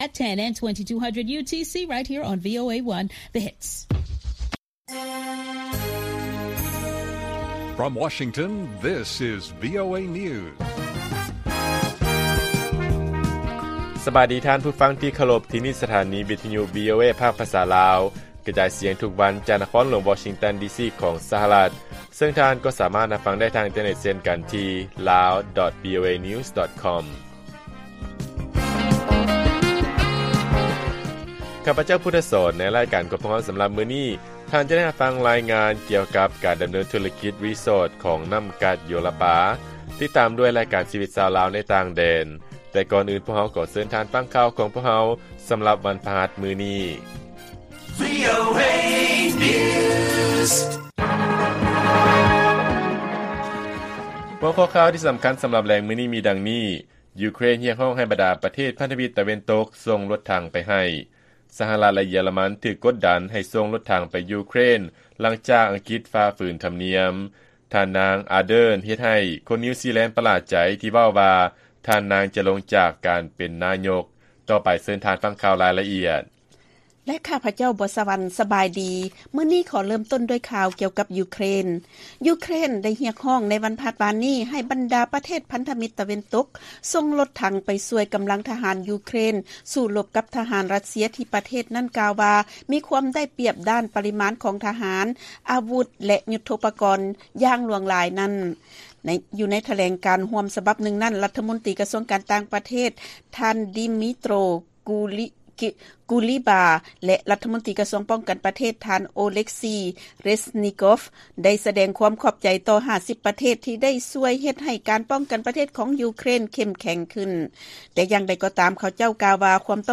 ລາຍການກະຈາຍສຽງຂອງວີໂອເອ ລາວ: ຢູເຄຣນຮຽກຮ້ອງໃຫ້ບັນດາປະເທດພັນທະມິດຕາເວັນຕົກ ສົ່ງລົດຖັງໄປໃຫ້